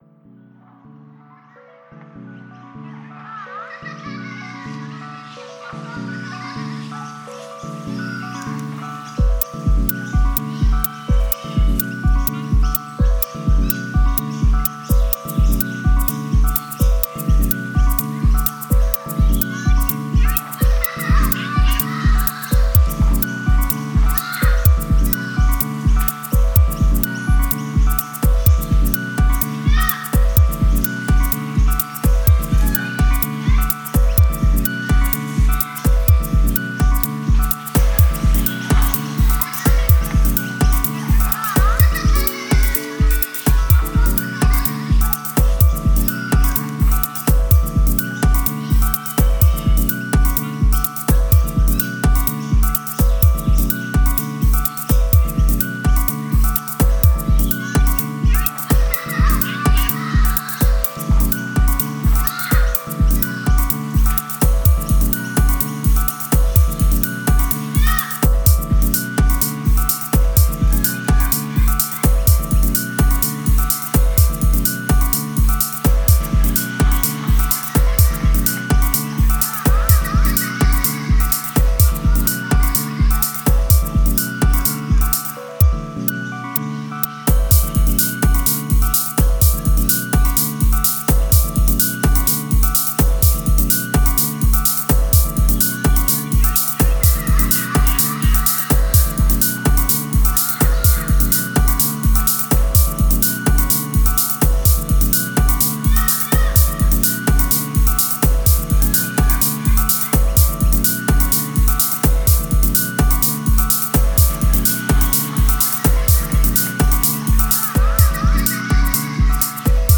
Genre: Dub Techno/Deep Techno.